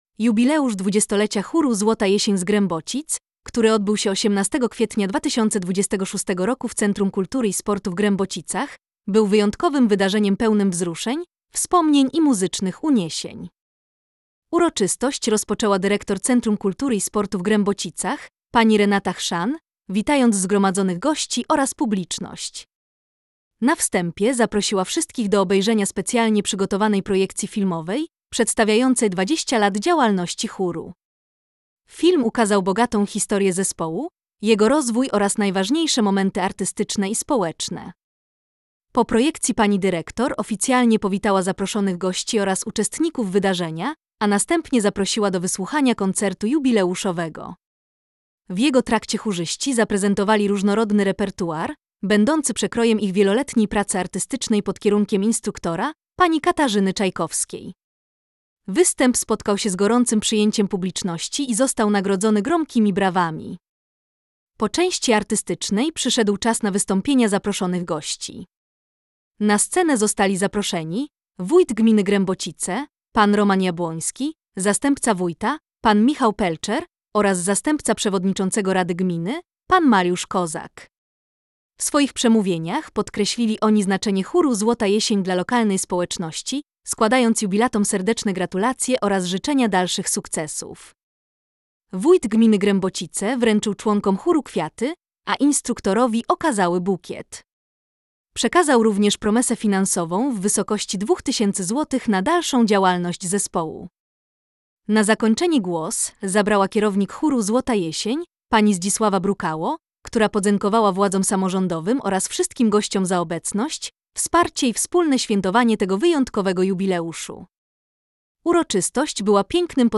Jubileusz 20-lecia chóru „Złota Jesień” z Grębocic, który odbył się 18 kwietnia 2026 roku w Centrum Kultury i Sportu w Grębocicach, był wyjątkowym wydarzeniem pełnym wzruszeń, wspomnień i muzycznych uniesień.
W jego trakcie chórzyści zaprezentowali różnorodny repertuar
Występ spotkał się z gorącym przyjęciem publiczności i został nagrodzony gromkimi brawami.